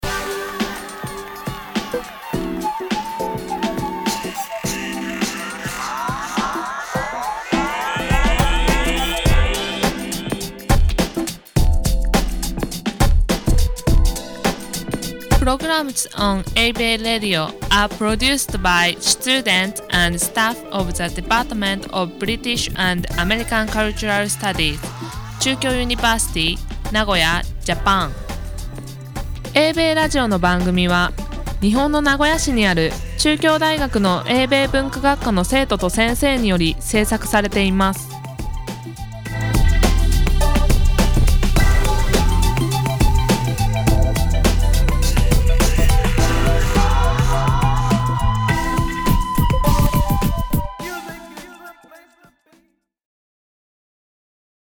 About Eibei Radio: Station ID
Programs on Eibei Radio are produced by students and staff of the